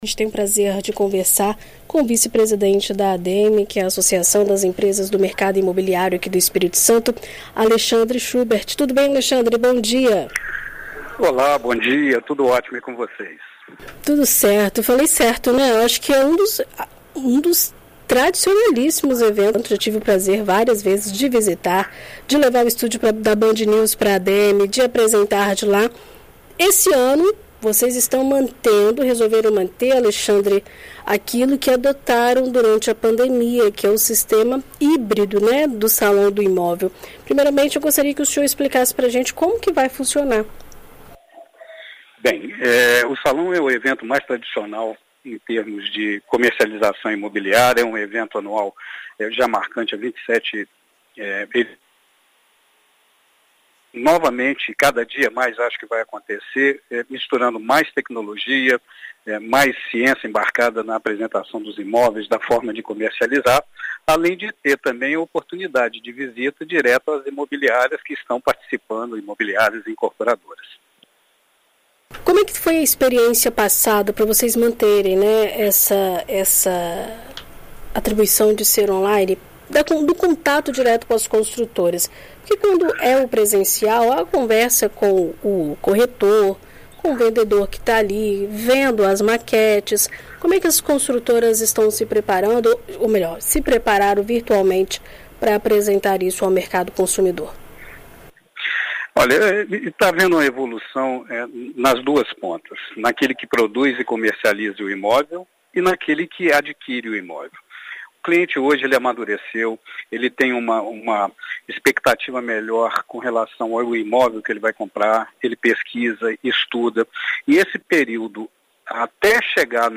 conversa com a BandNews FM ES sobre o evento.